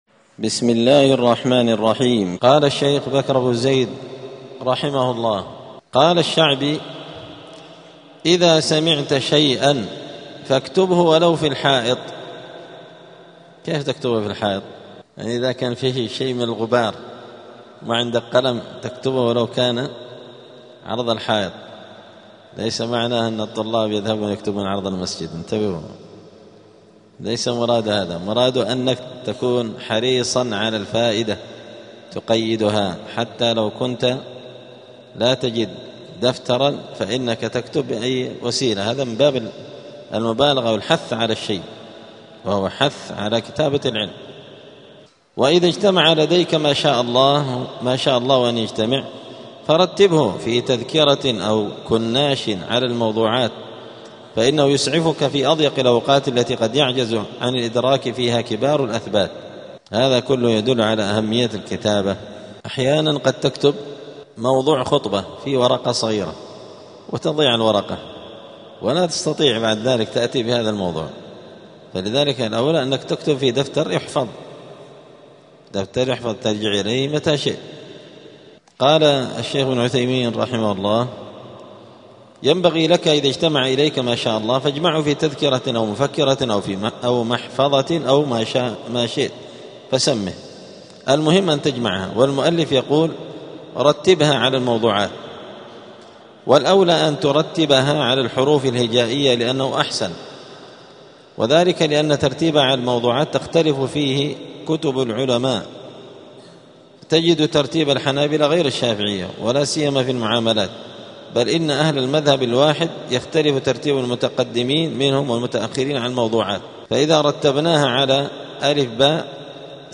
*الدرس التاسع والخمسون (59) فصل آداب الطالب في حياته العلمية {حفظ العلم كتابة}.*
دار الحديث السلفية بمسجد الفرقان قشن المهرة اليمن